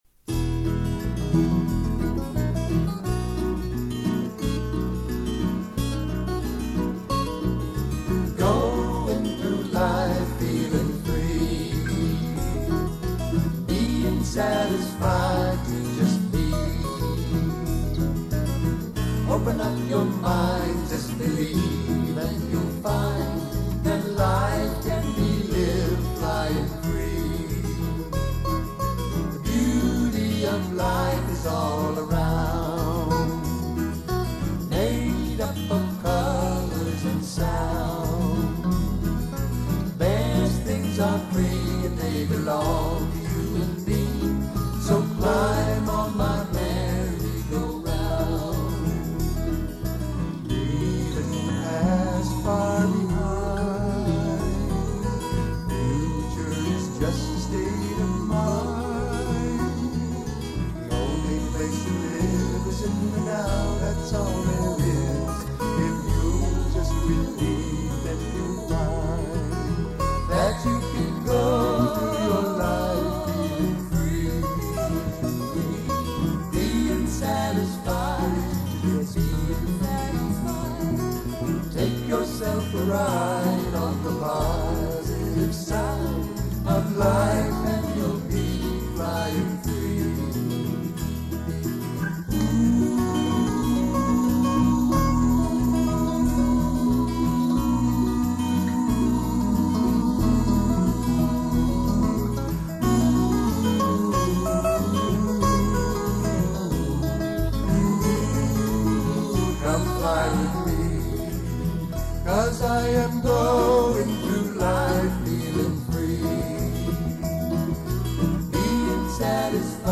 Major (Shankarabharanam / Bilawal)
8 Beat / Keherwa / Adi
Medium Fast
4 Pancham / F
1 Pancham / C